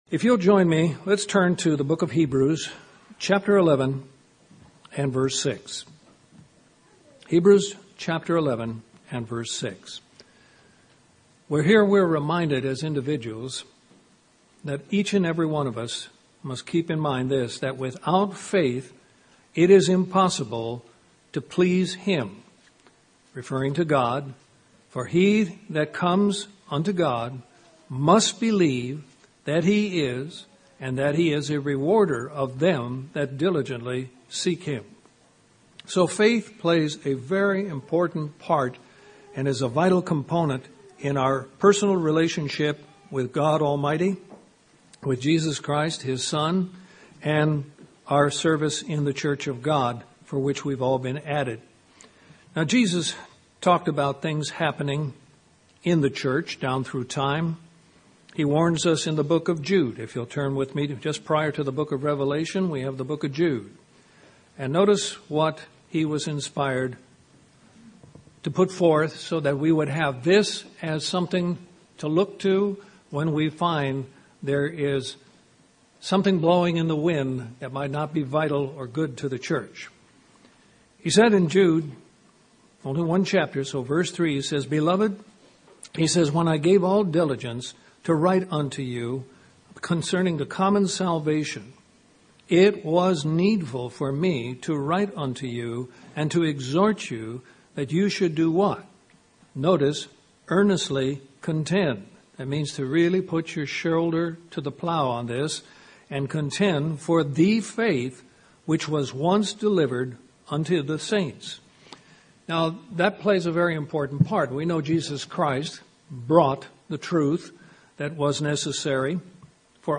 People today are constantly being bombarded from every direction that is causing faith and confidence in the word of God to falter. This sermon addresses the importance of keeping God as our focus and a reminder to keep clinging to the faith once delivered.